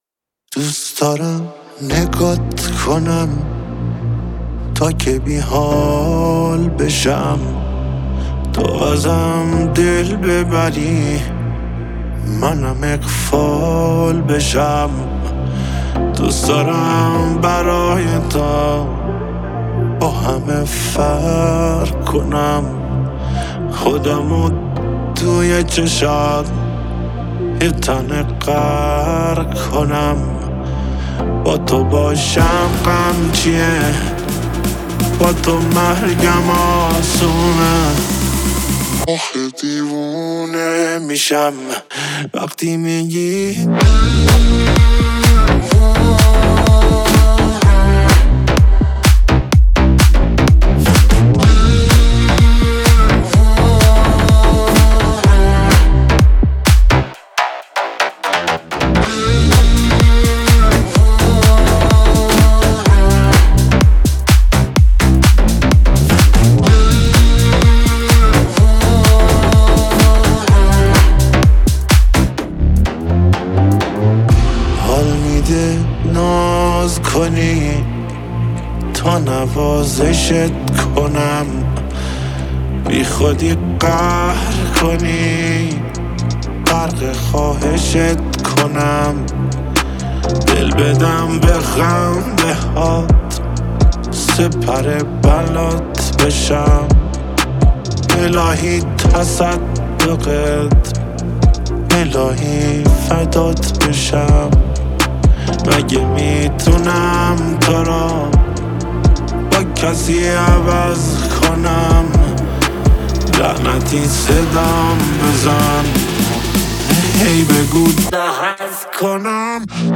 با صدای دلنشین